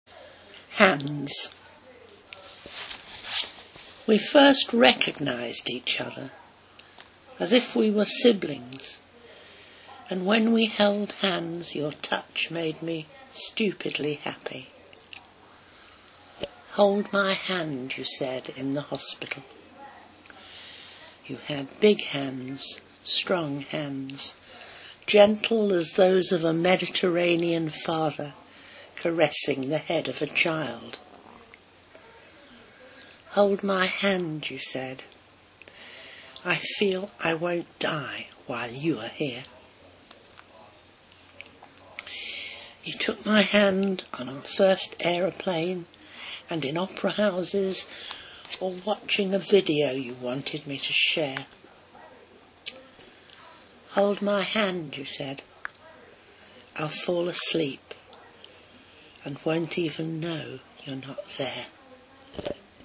Elaine Feinstein reading her own poetry